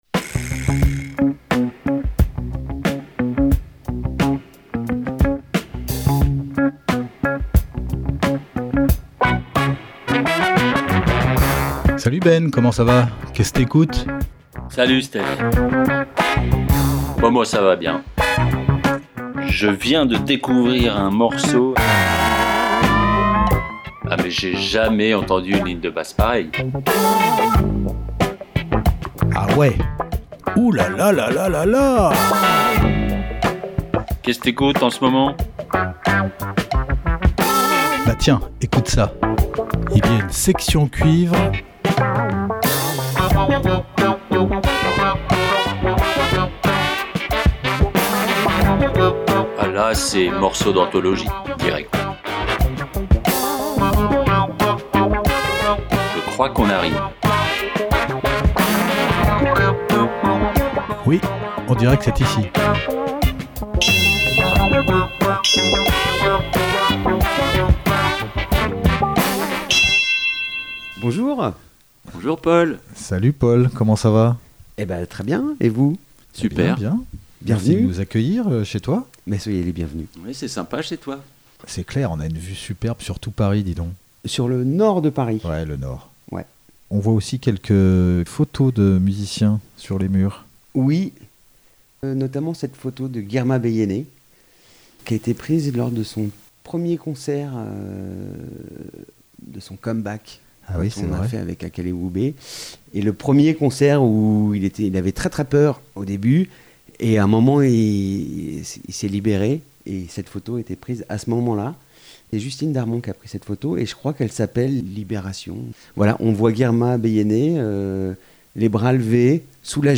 Découvertes musicales Classique & jazz